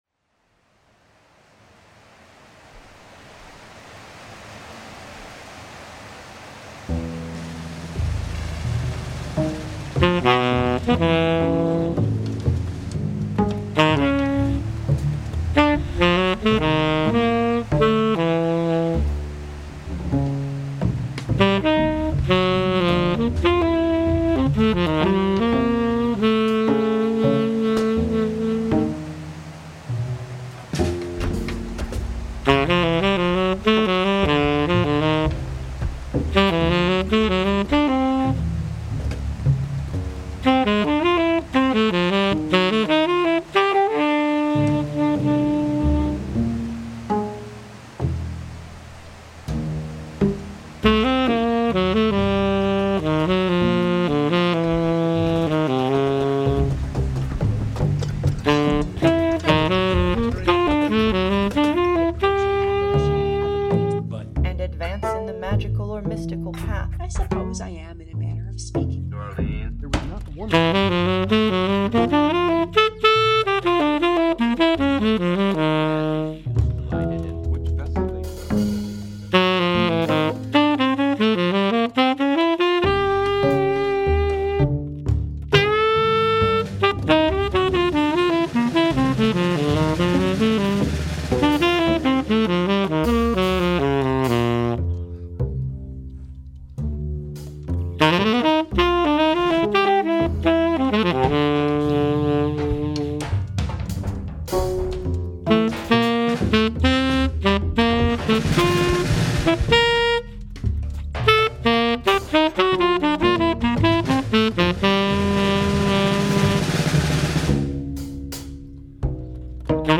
live streamed
Live Music, Saxophone
Additional (live mashed) sonic fictions